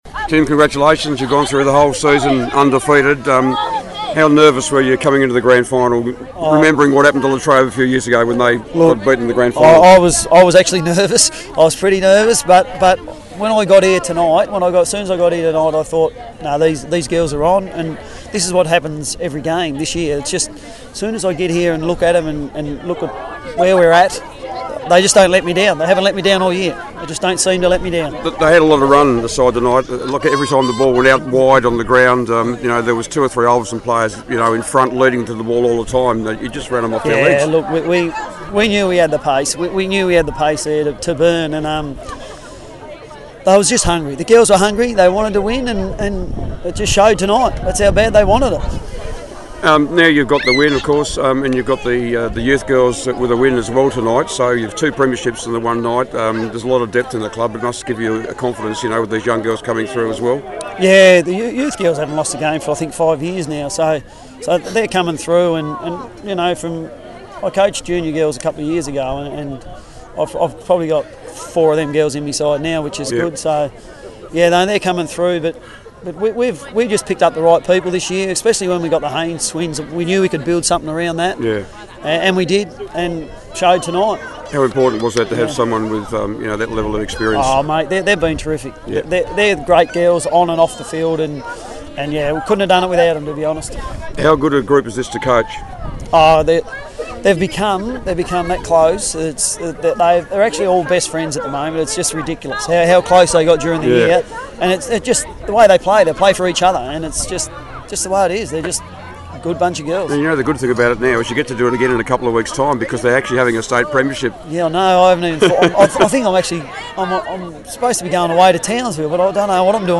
at the grand finals